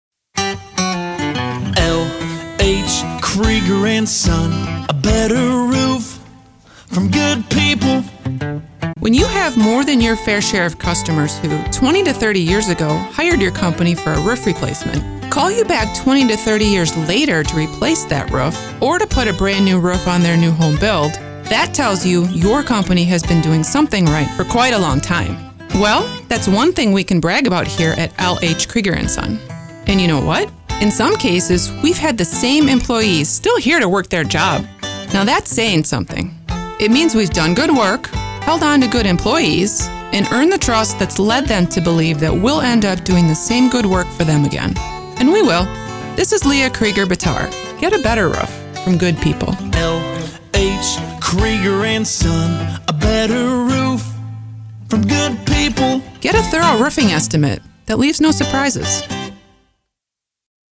LH Krueger & Son - 60 sec Radio Ad 1 Repeat Customers